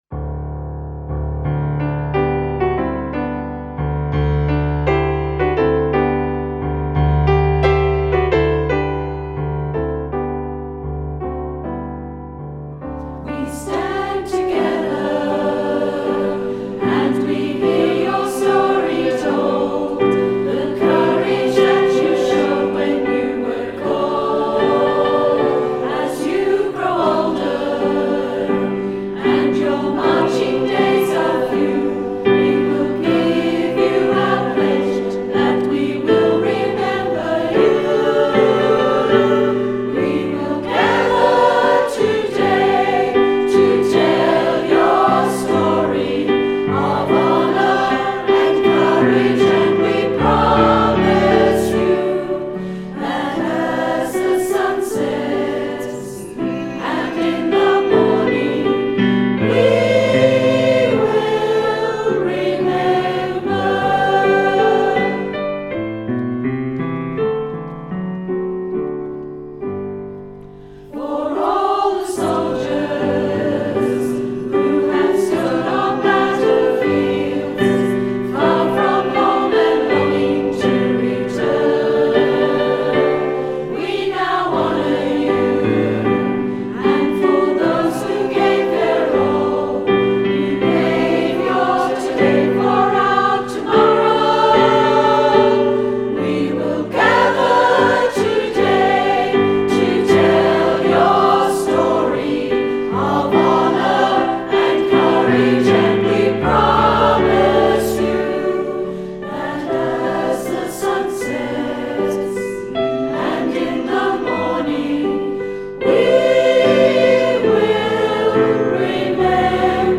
A special ANZAC song
piano accompaniment